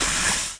projbomb_burn.wav